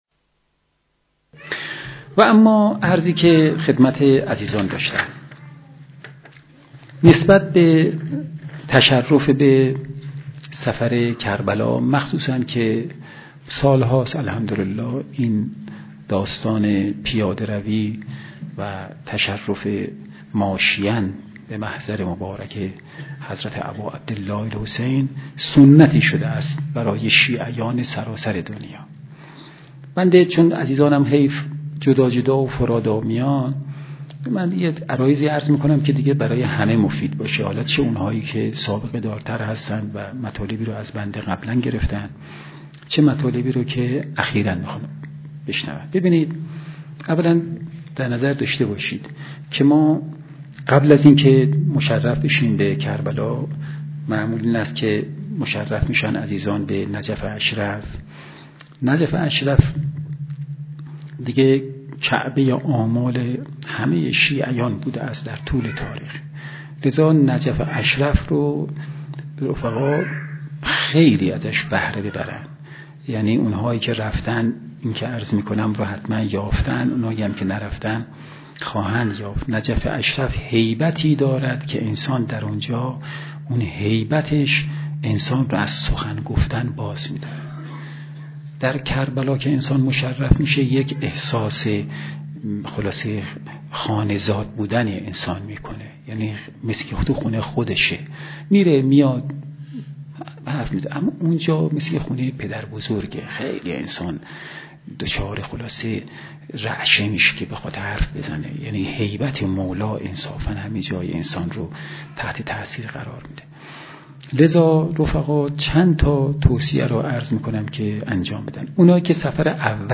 گر چه مجموعه این توصیه ها و دستورات بعدا در قالب کتاب ادب حضور کامل تر و منظم تر ارائه شد، اما این فایل صوتی همچنان حلاوت و حرارت خاص خود را دارد.